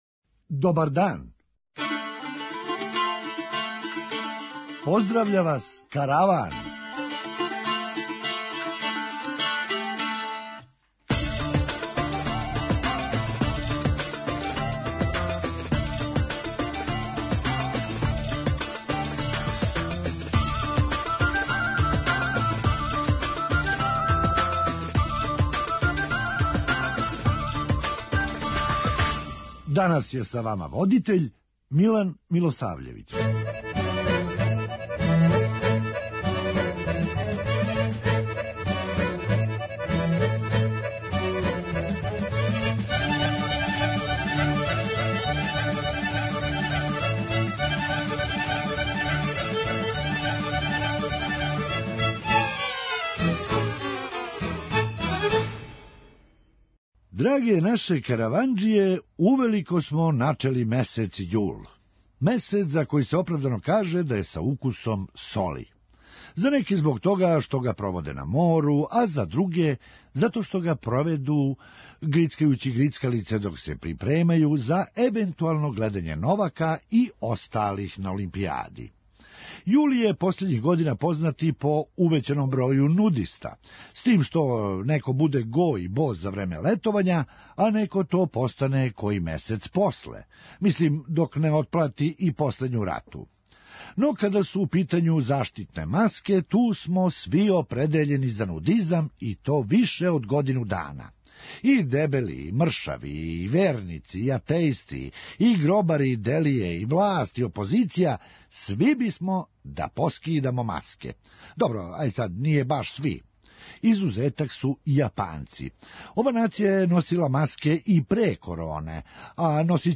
Хумористичка емисија
И, ако му неко сада притисне бубуљицу - излетеће сав сенф! преузми : 9.28 MB Караван Autor: Забавна редакција Радио Бeограда 1 Караван се креће ка својој дестинацији већ више од 50 година, увек добро натоварен актуелним хумором и изворним народним песмама.